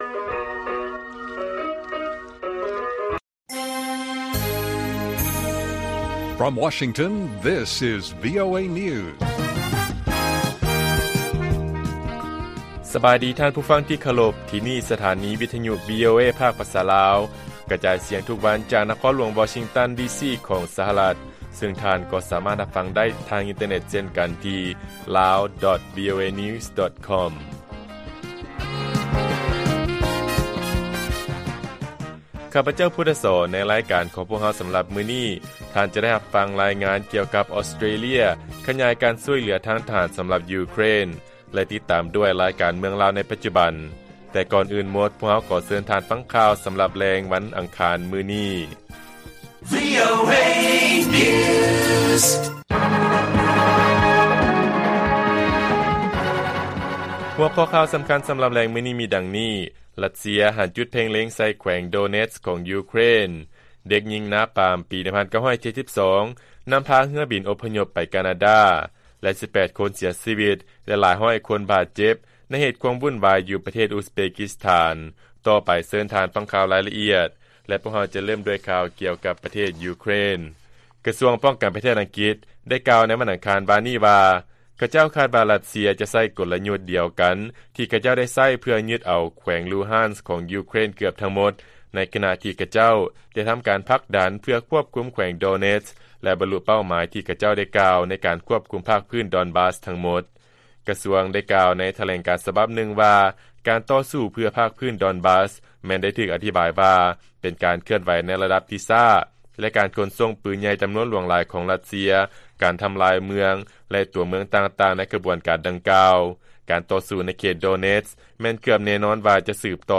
ລາຍການກະຈາຍສຽງຂອງວີໂອເອ ລາວ: ຣັດເຊຍ ຫັນຈຸດເພັ່ງເລັງໃສ່ແຂວງ ໂດເນັດສ໌ ຂອງຢູເຄຣນ